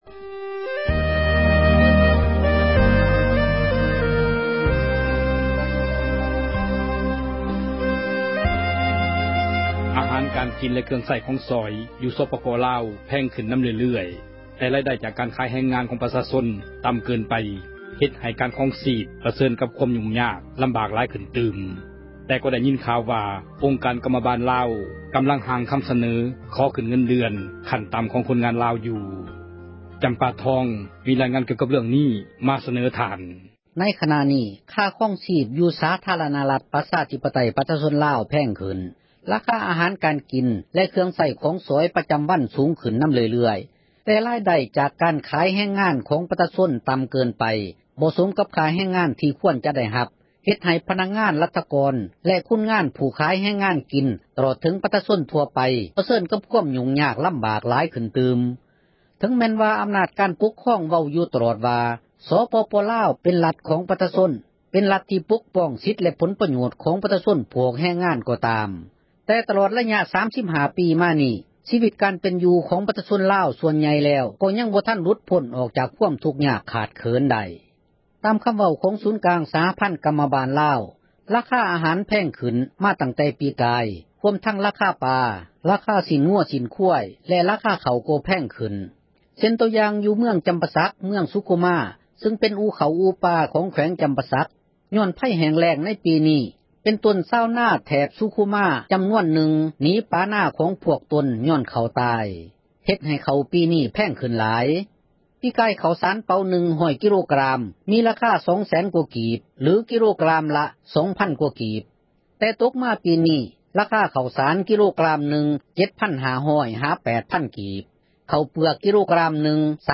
ຄ່າຄອງຊີພໃນລາວ ສູງຂື້ນເລື້ອຍໆ — ຂ່າວລາວ ວິທຍຸເອເຊັຽເສຣີ ພາສາລາວ